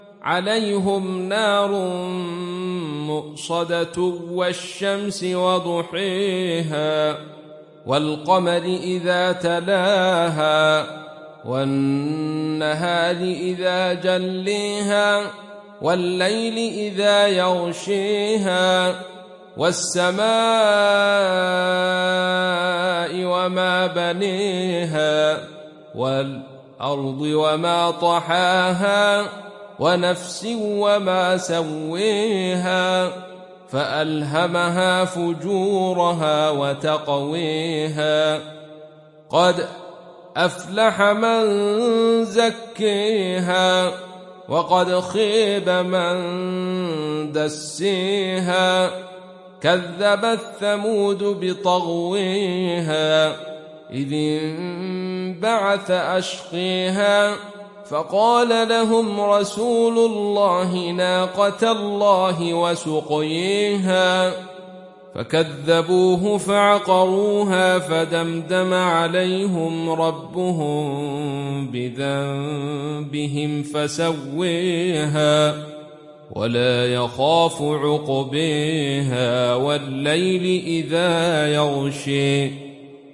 Sourate Ash Shams Télécharger mp3 Abdul Rashid Sufi Riwayat Khalaf an Hamza, Téléchargez le Coran et écoutez les liens directs complets mp3